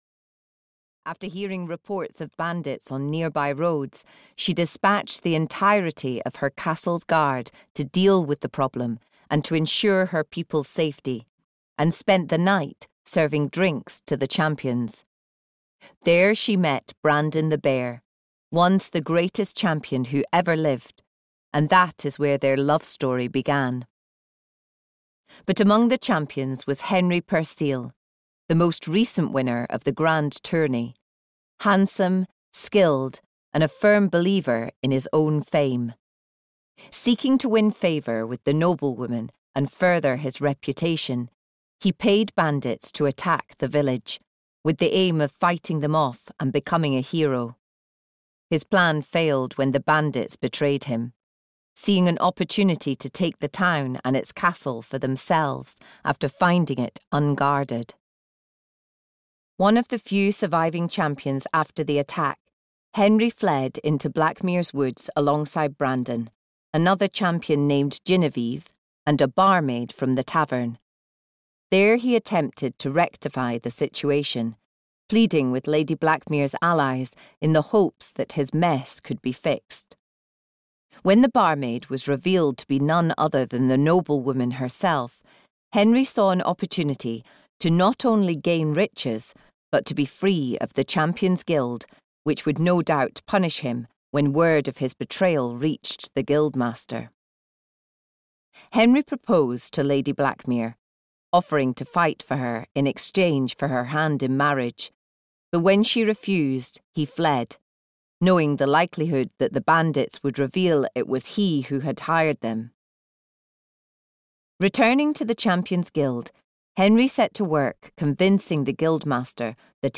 Audio knihaThe Harpy and the Dragon (EN)
Ukázka z knihy